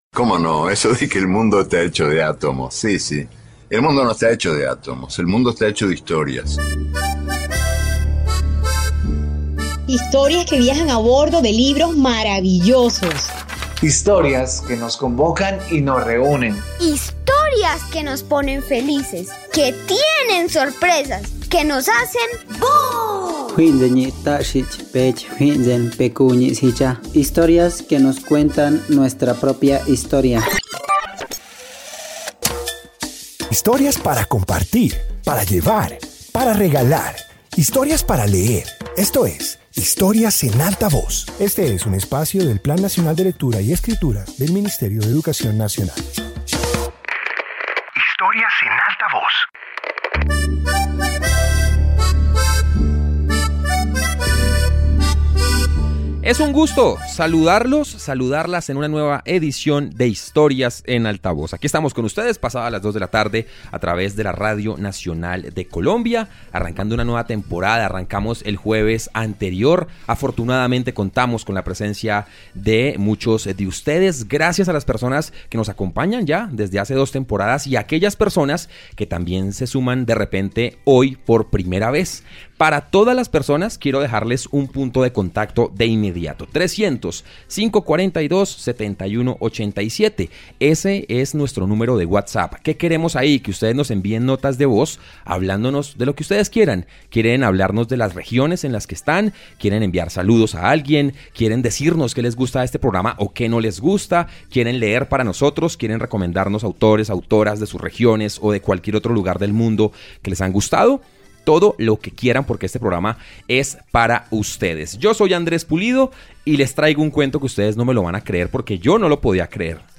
Introducción Este episodio de radio reúne narraciones sobre personas que han recorrido diversos territorios. Presenta rutas, curiosidades y encuentros que amplían la comprensión de lugares y culturas alrededor del mundo.